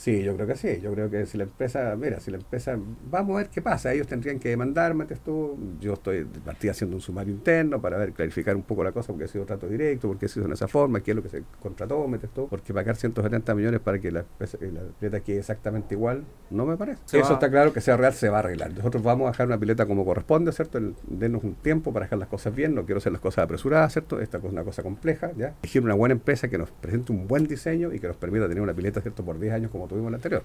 alcalde-osorno-pileta.mp3